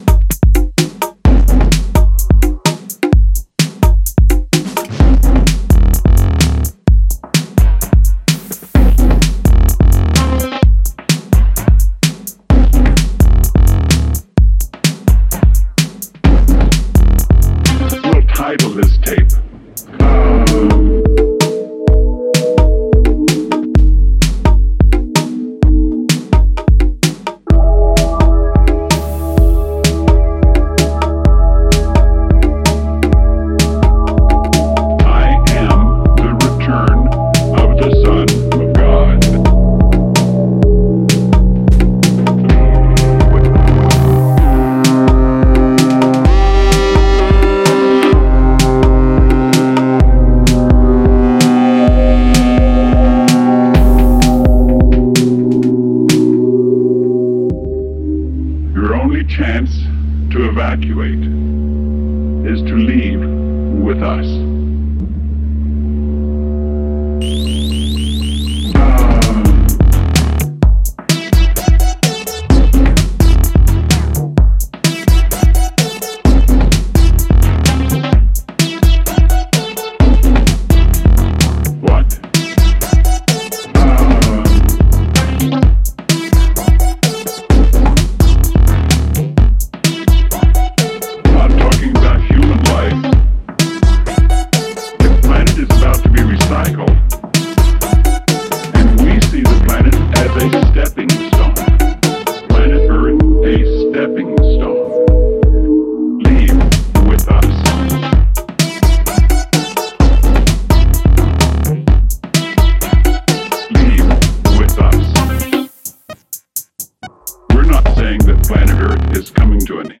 Genre Breaks , Electro , Techno